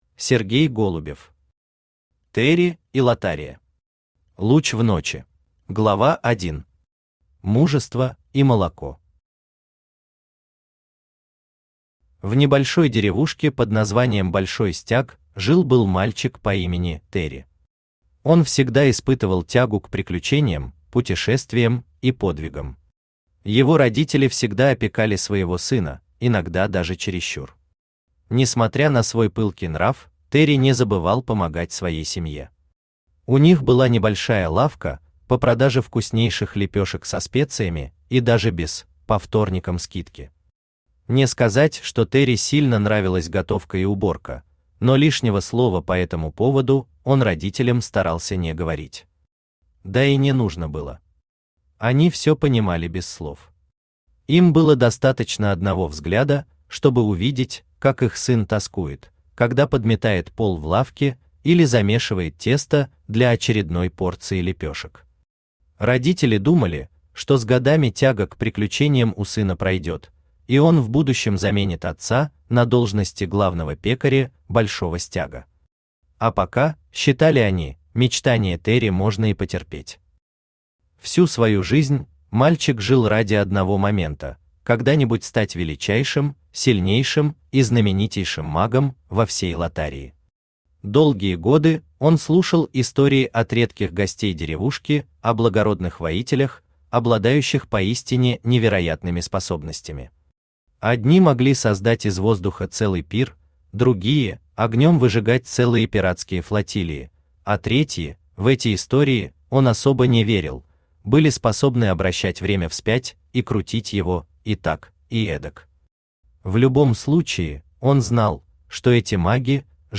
Аудиокнига Тэрри и Латтария. Луч в ночи | Библиотека аудиокниг
Луч в ночи Автор Сергей Голубев Читает аудиокнигу Авточтец ЛитРес.